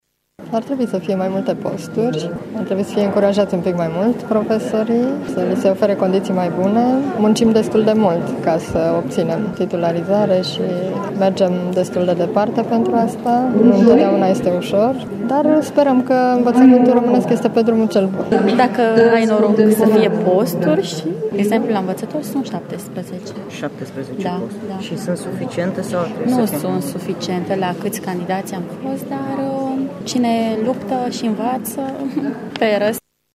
Profesorii mureșeni prezenți la ședința de azi spun că posturile nu sunt suficiente: